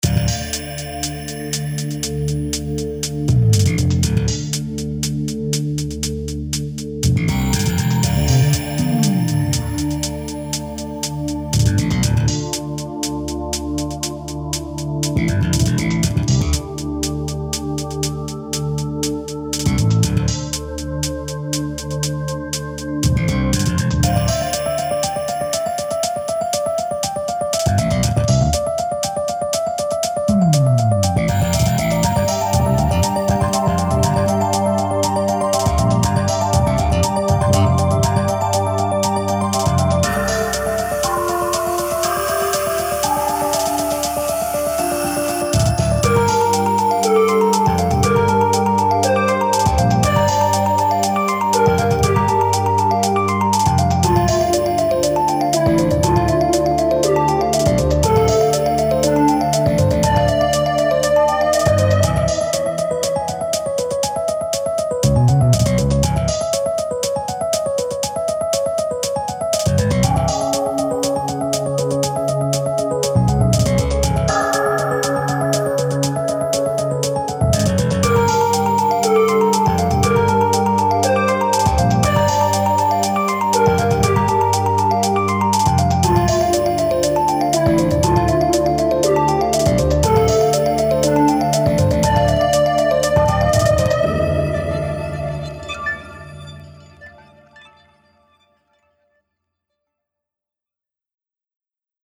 恐怖や、疑念、不安などを煽るようなシーンに。